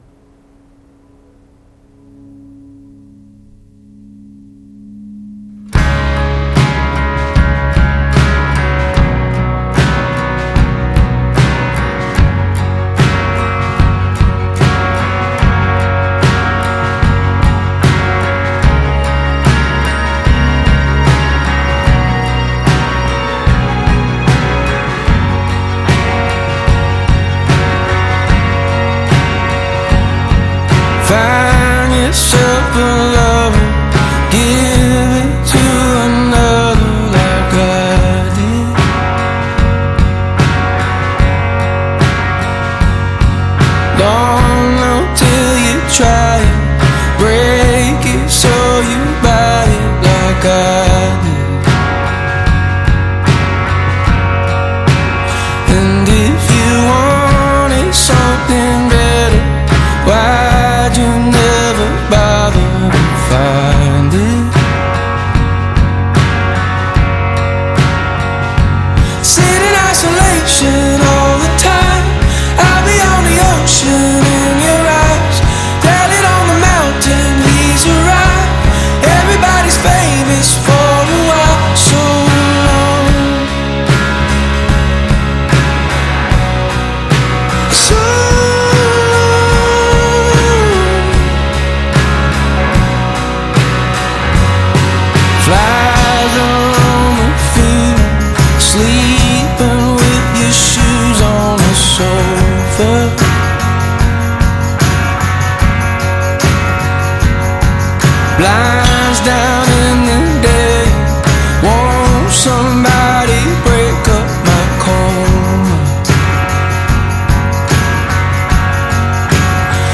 Модная инди фолк рок группа